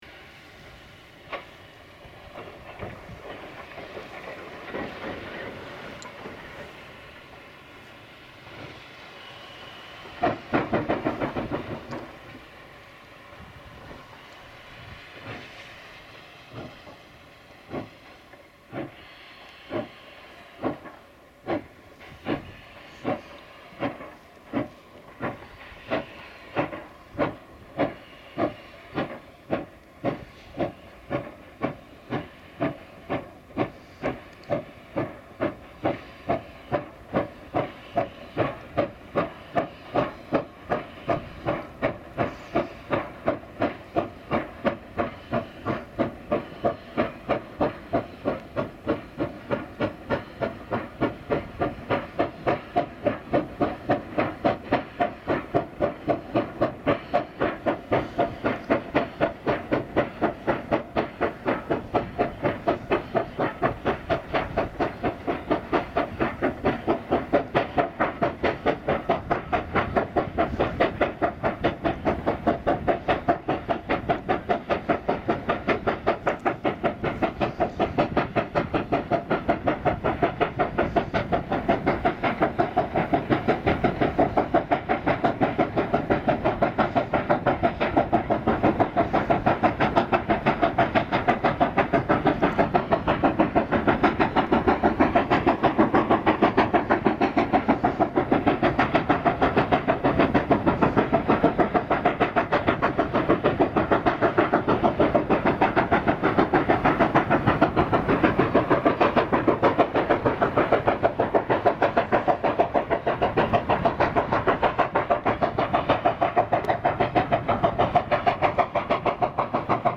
Sandaoling: Nach einem Signalhalt kämpft sich JS 8225 mit einem hinauf auf den Abraumberg, wo das aus dem Tagebau gelöste taube Gestein abgeladen wird.